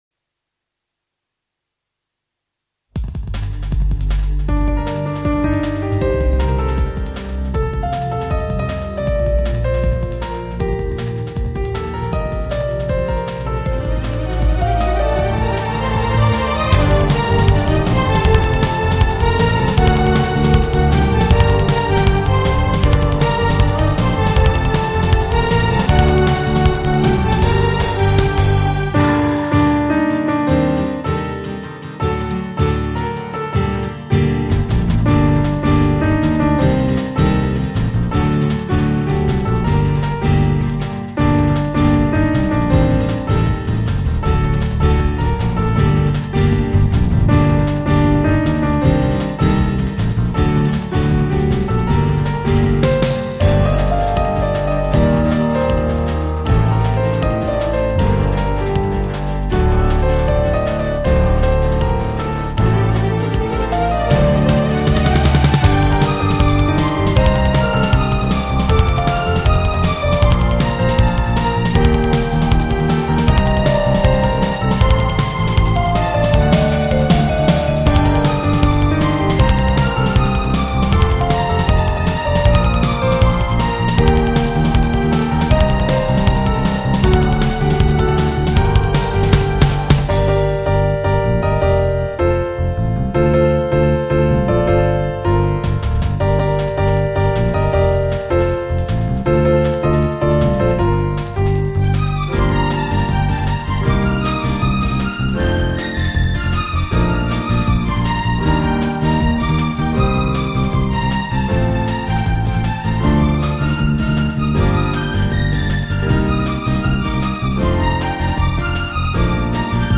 （Pops　4：04）
そういった悲しい未来に対し強く立ち向かっていく